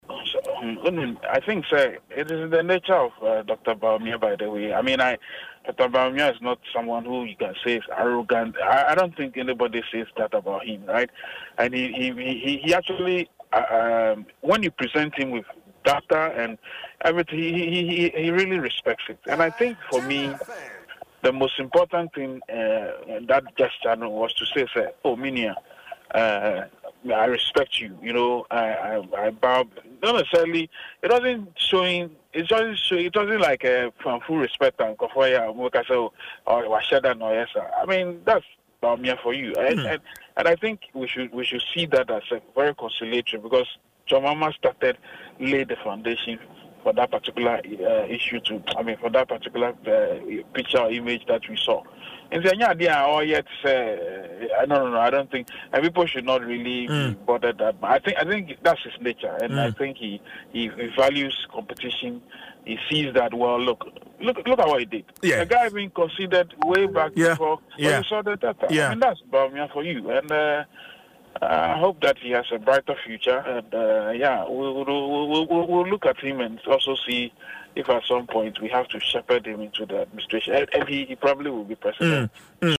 But in an interview on Adom FM’s, morning show , Dwaso Nsem , Franklin Cudjoe shared his perspective on the moment. He explained that the bow was a true reflection of Dr. Bawumia’s character, rather than a carefully orchestrated act for the cameras.